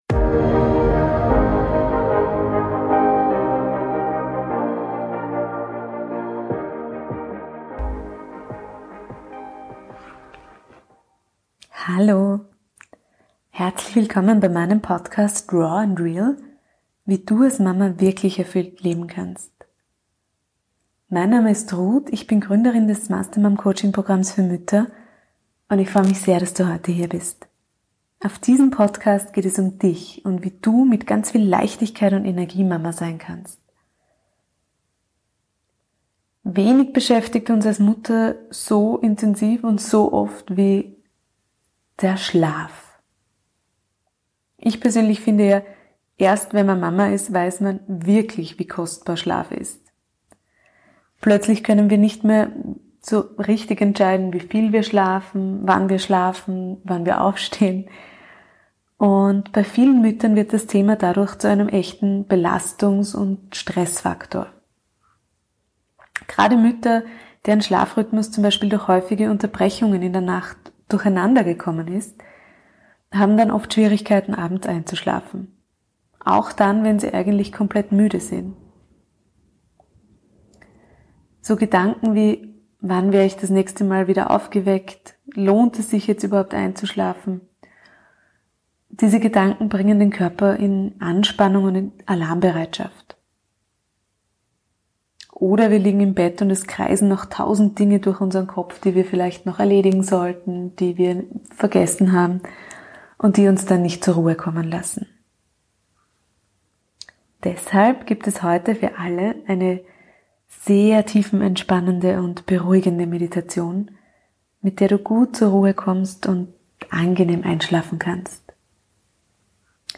Deshalb gibt es heute für dich eine tiefenentspannende und beruhigende Meditation, mit der du gut zur Ruhe kommst und angenehm einschlafen kannst.